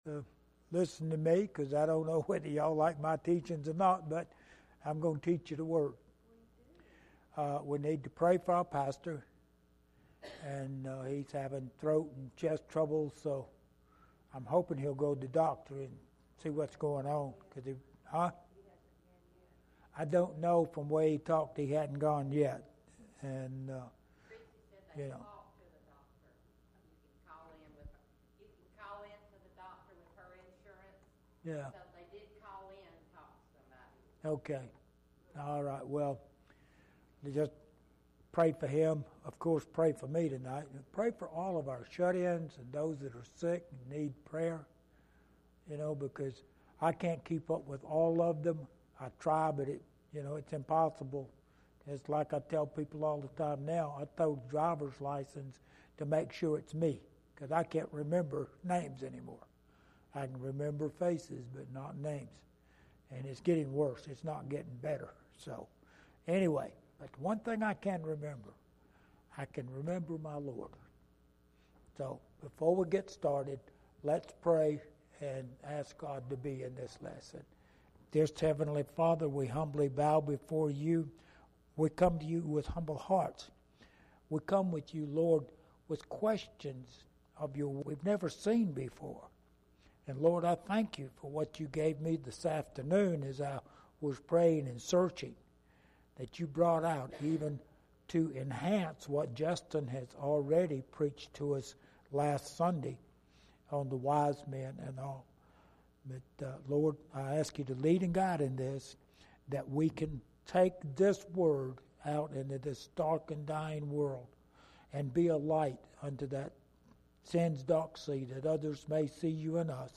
Matthew 2:4-6 Service Type: Midweek Meeting « From The Cradle To The Cross Pt. 2